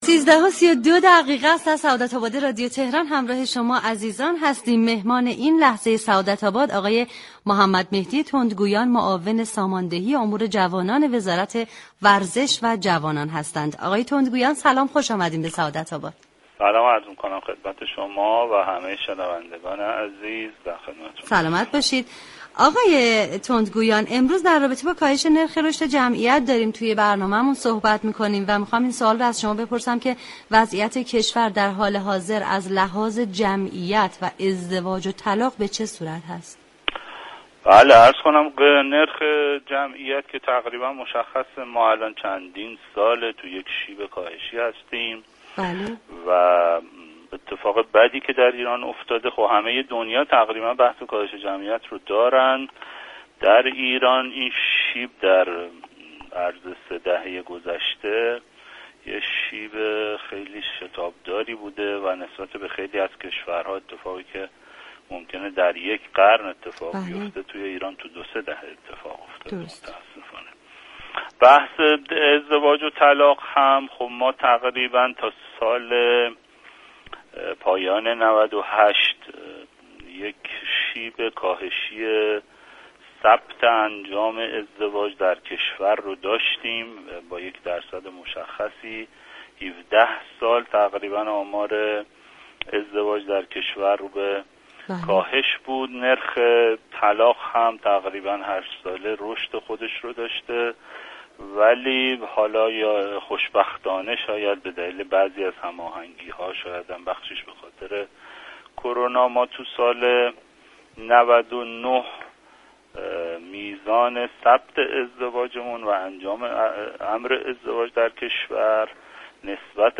به گزارش پایگاه اطلاع رسانی رادیو تهران، محمدمهدی تندگویان معاون ساماندهی جوانان وزارت ورزش و جوانان در گفتگو با برنامه سعادت آباد درباره وضعیت كنونی كشور از نظر ازدواج، طلاق و زاد و ولد گفت: از نرخ جمعیت طی چند سال اخیر در شیب كاهشی هستیم اگرچه در تمامی كشورهای جهان این وضعیت وجود دارد اما در كشور ما این شیب بسیار سریعتر بوده به طوریكه اگر این روند را در كشورهای دیگر فرآیندی در یك قرن در نظر بگیریم كاهش روند جمعیت در كشور ما در 3 دهه اتفاق افتاده است.